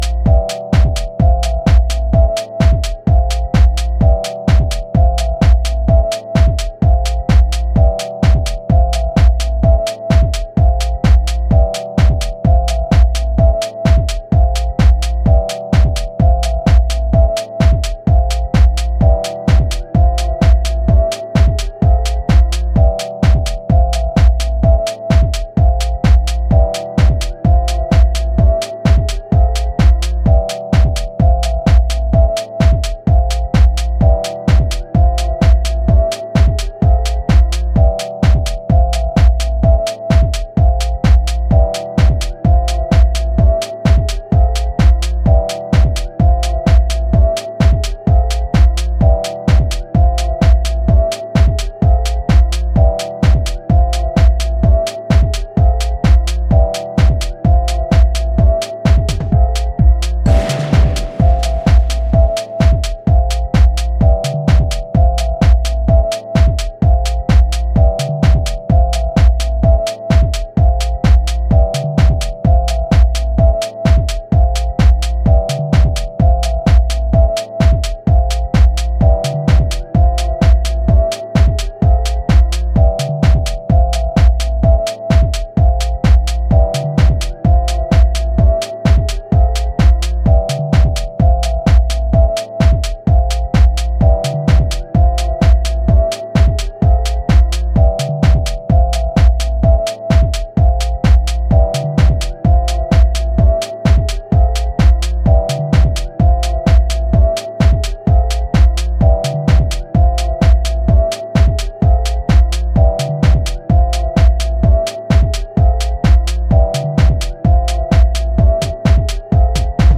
燻んだ質感のシンセとキックの質感がパーティー終盤をメランコリックなムードで飾る